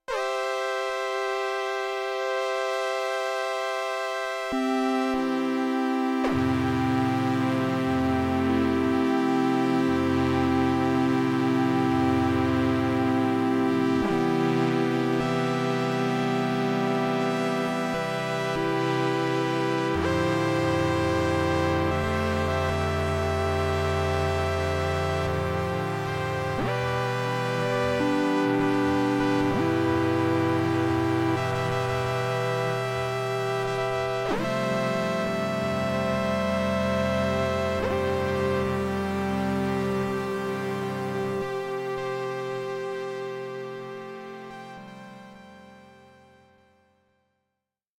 Here’s the first sound I made, no FX